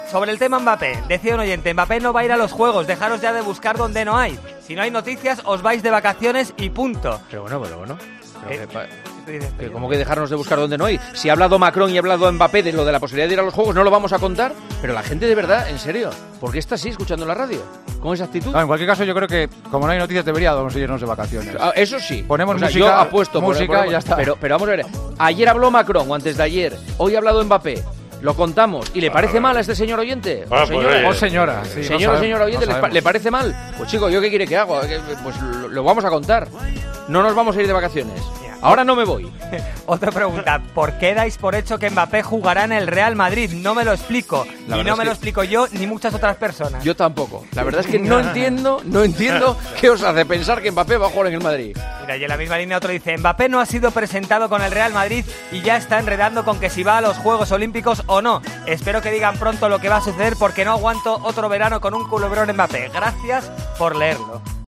La respuesta de Juanma Castaño a un oyente que se queja por Mbappé: "Ahora no me voy de vacaciones"